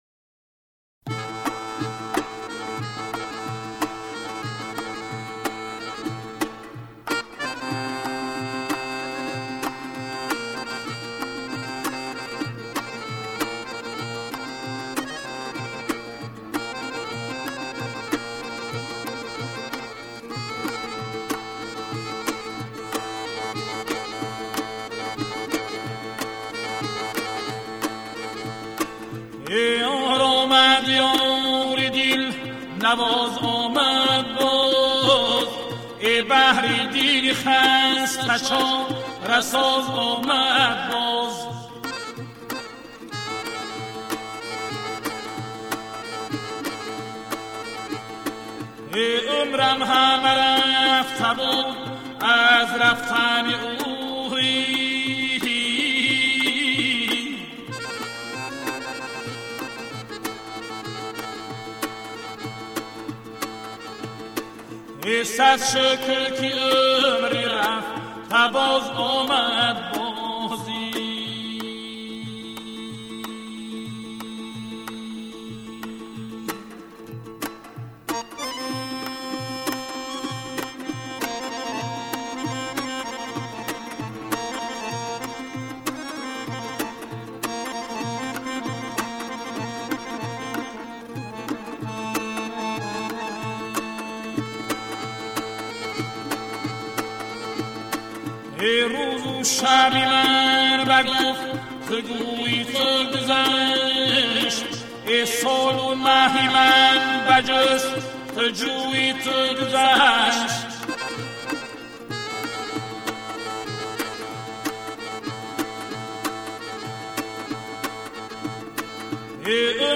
Мусиқа ва тарона